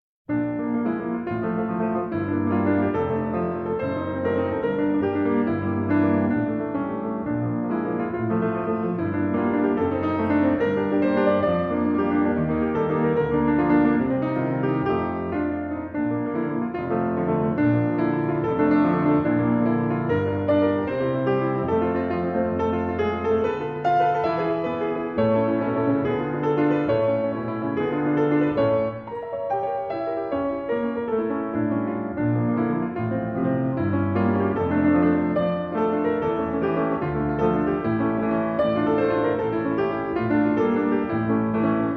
77 WERKE FÜR KLAVIERSOLO · EIN- UND ZWEIHÄNDIG